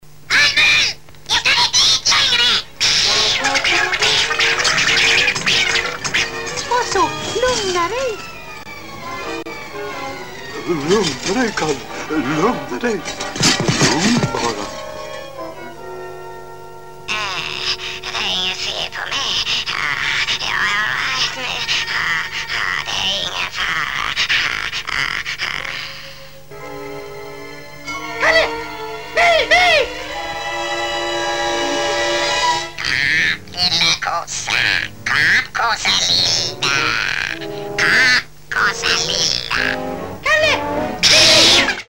där hör man också Musse och Långben en aning
Han låter ju inte riktigt klok..  :oSom läskiga tjejen i The Grudge fast pitchad..
Han lät som en smurf, som satt nåt i halsen! :D
Han lät som en elak häxa som fått en irriterande förkylning.  ;D